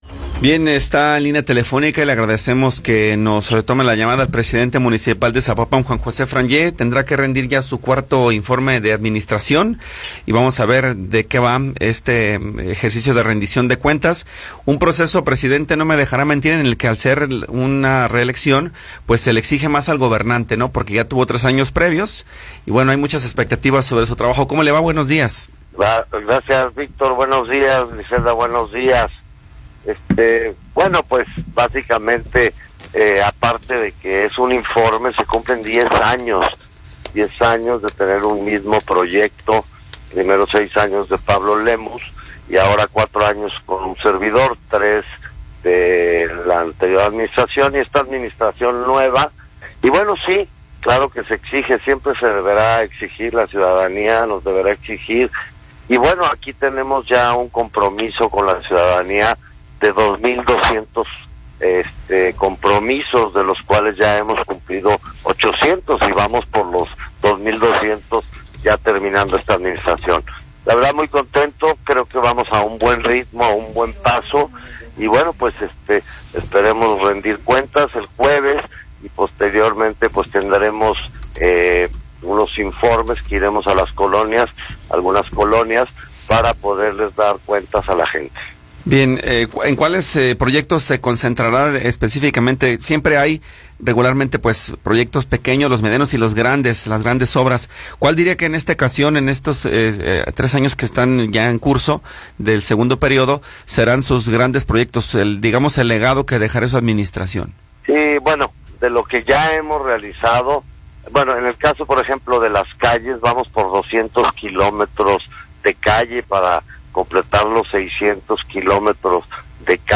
Entrevista con Juan José Frangie